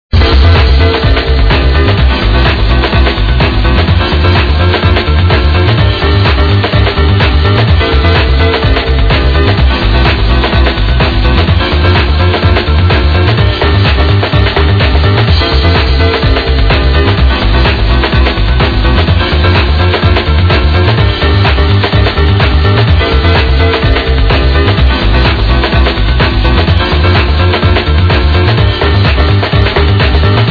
live set.